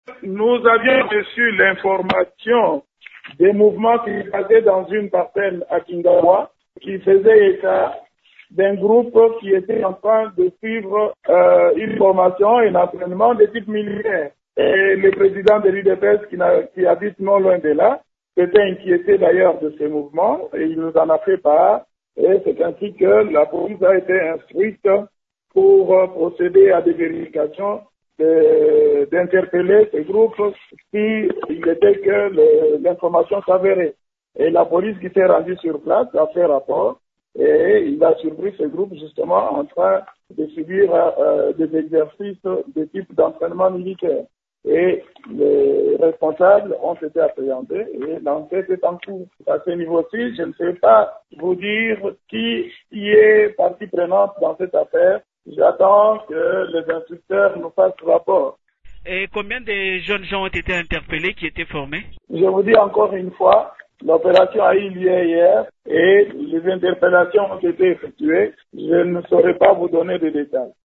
Le vice-Premier ministre et ministre de l’Intérieur, Gilbert Kankonde, joint au téléphone ce jeudi 27 août après-midi affirme qu’une enquête est en cours pour tirer au clair cette affaire.